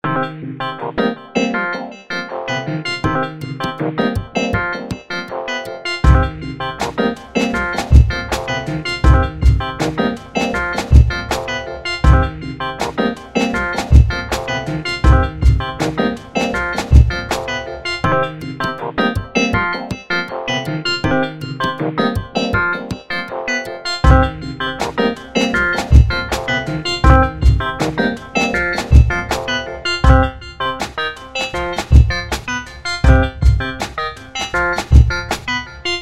He created a bunch of loops that you can use in your own compositions if you want.